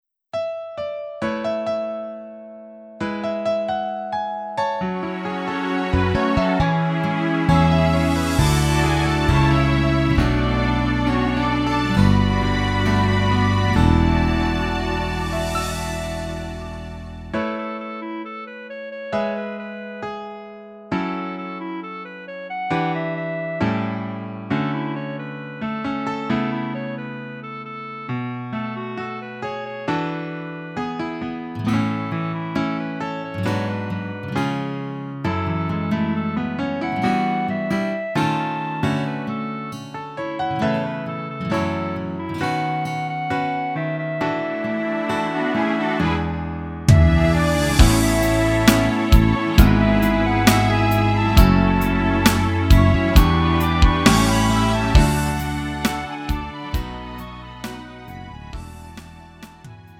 음정 -1키 3:29
장르 가요 구분 Lite MR